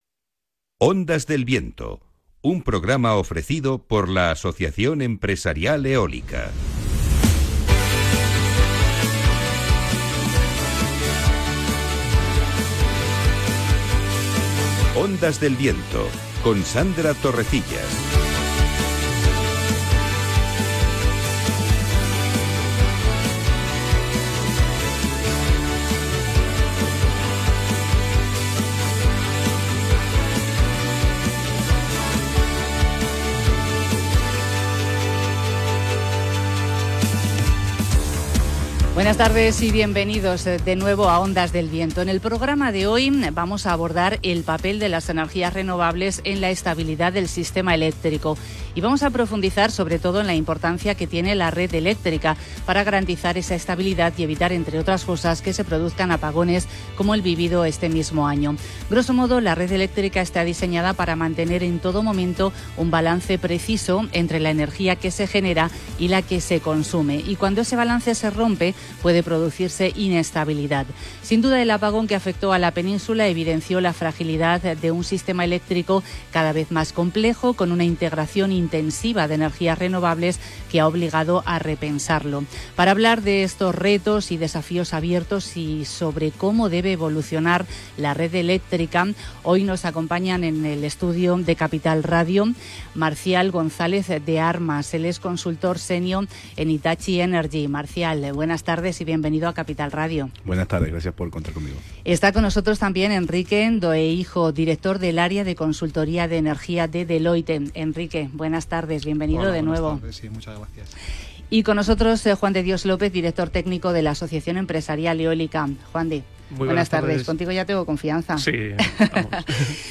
En este nuevo episodio de Ondas del Viento, el programa radiofónico del sector eólico en la emisora Capital Radio, hemos hablado sobre cómo debe de evolucionar la red eléctrica y la integración de las renovables en ella.